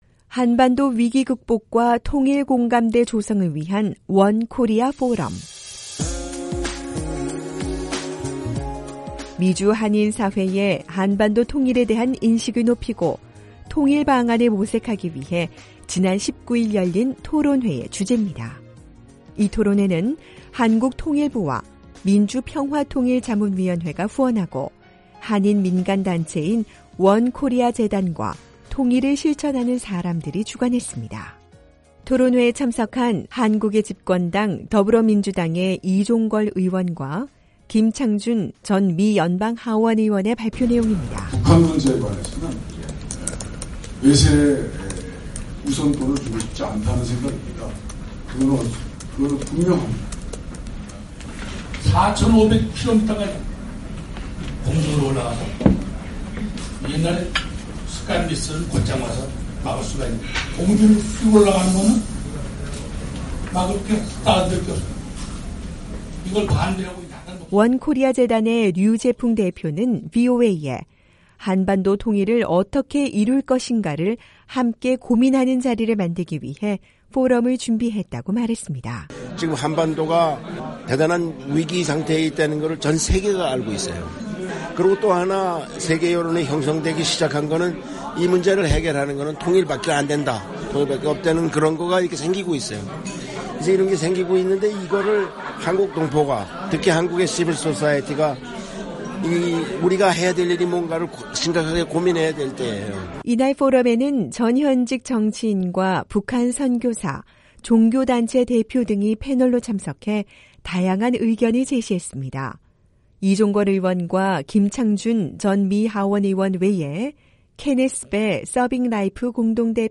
지난 19일 미국 워싱턴에서 열린 '한반도 위기 극복과 통일 공감대 조성을 위한 원코리아 포럼'에서 북한에 억류됐다 풀려난 한국계 미국인 케네스 배 선교사가 발언하고 있다.